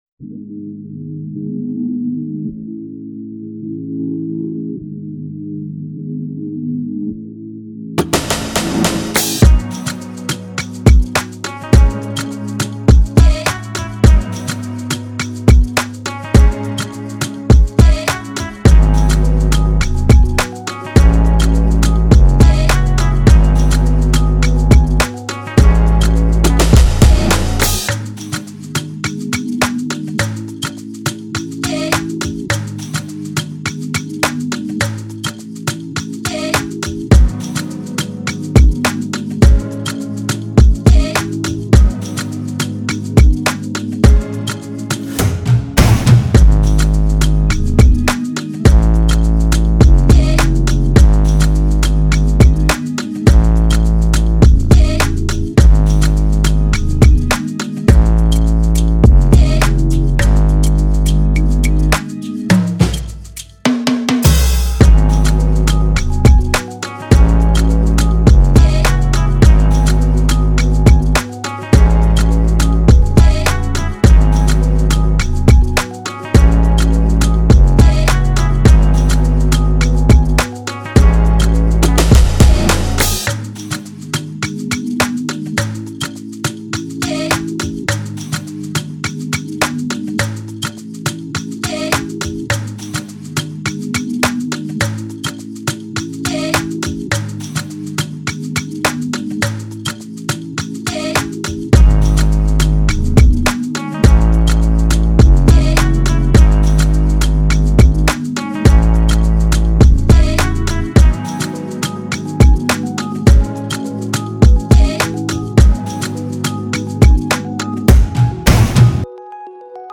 Dancehall/Afrobeats Instrumentals